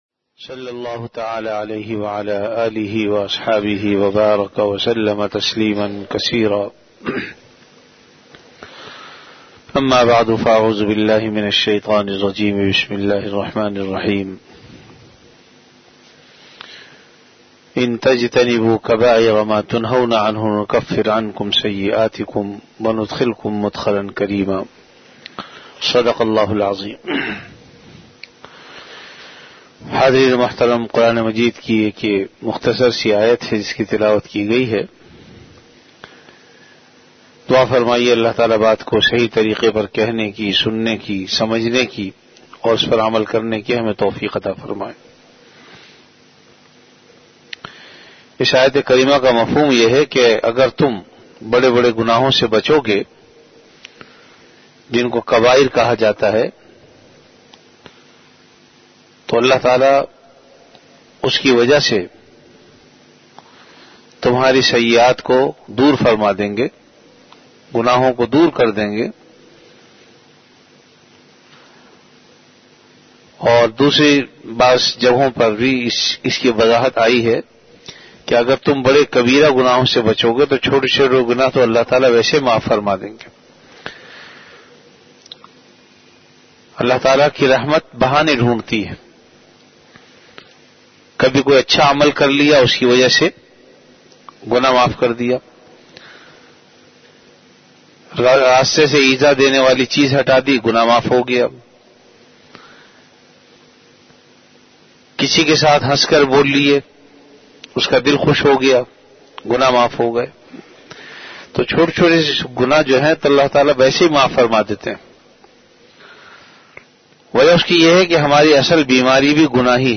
Delivered at Jamia Masjid Bait-ul-Mukkaram, Karachi.
Majlis-e-Zikr · Jamia Masjid Bait-ul-Mukkaram, Karachi